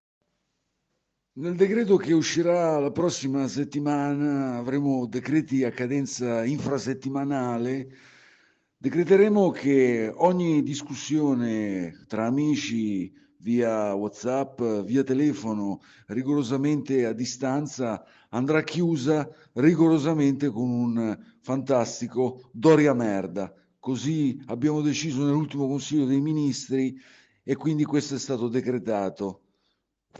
INTERVISTA A CONTE!
Esclusiva DNA Rossoblu, il Premier Conte parla ai nostri microfoni e specifica un passaggio fondamentale sul nuovo DPCM.